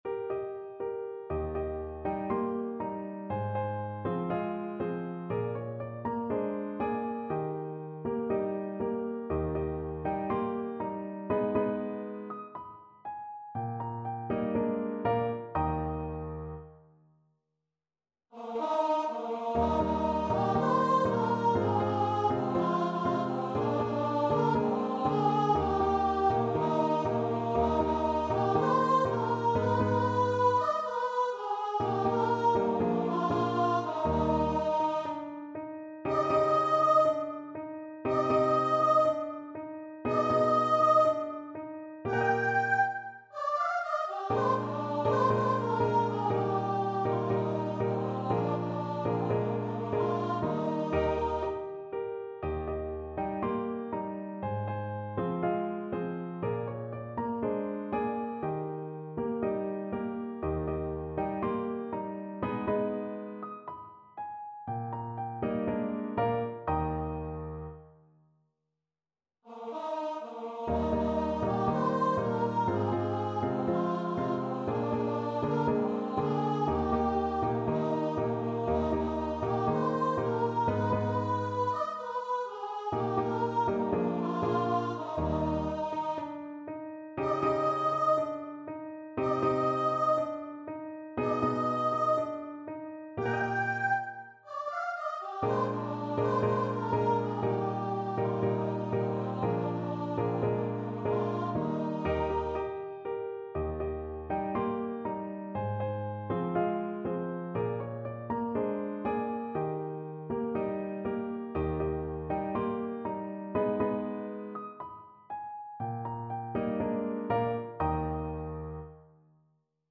Gracioso = 60
4/4 (View more 4/4 Music)
Bb4-G6
Classical (View more Classical Voice Music)
world (View more world Voice Music)